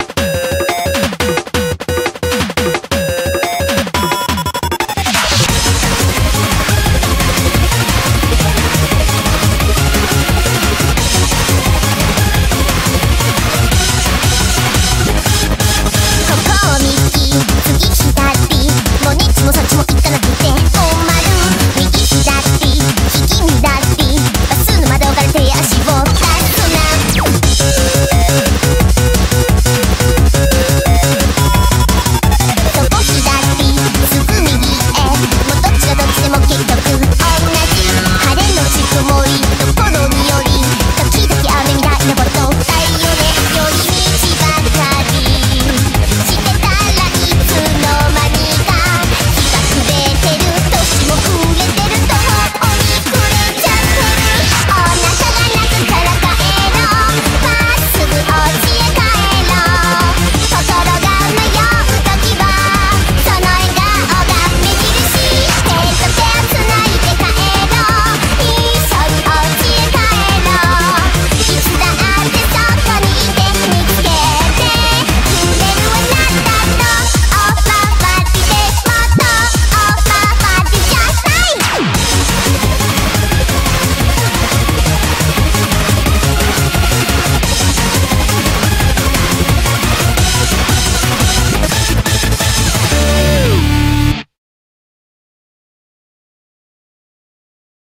BPM175--1
Audio QualityMusic Cut